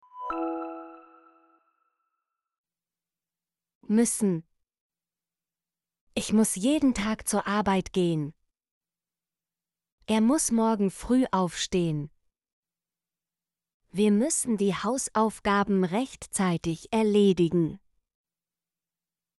müssen - Example Sentences & Pronunciation, German Frequency List